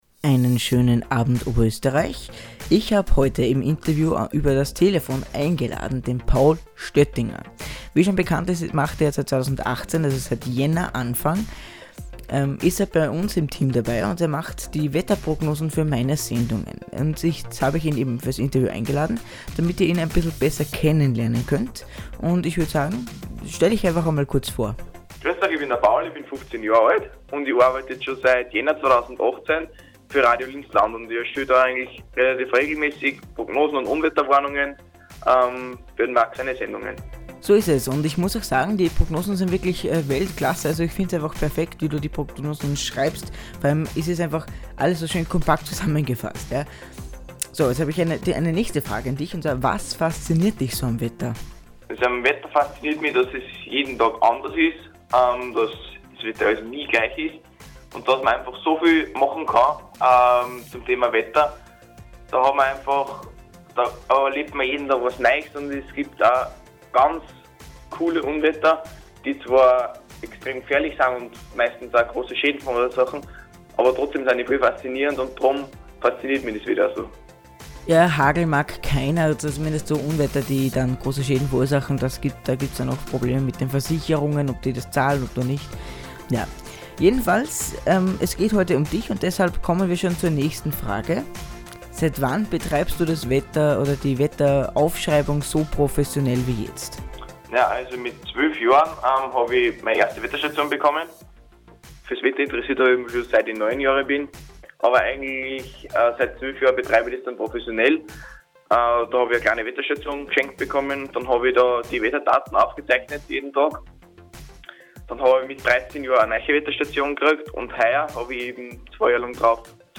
Hier finden Sie alle Medienauftritte wie Zeitungsartikel oder Radio - Interviews der Privaten Wetterstation Lambach.